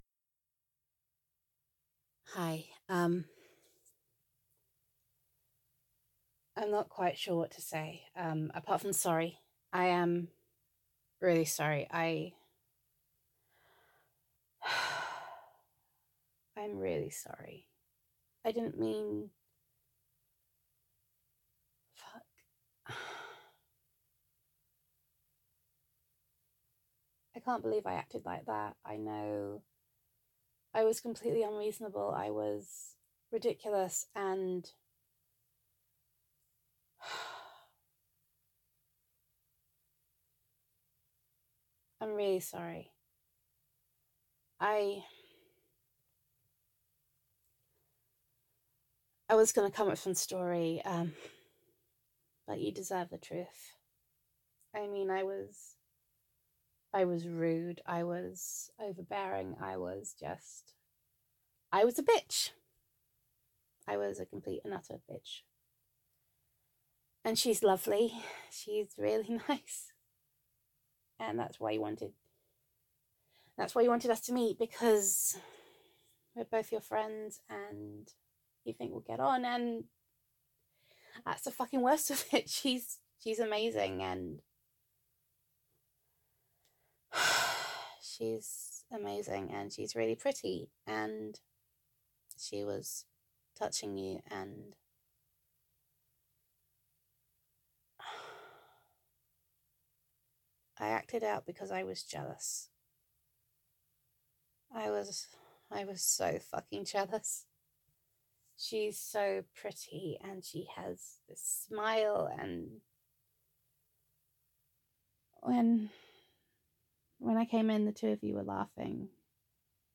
[F4A]
[Voicemail]
[Best Friend Roleplay]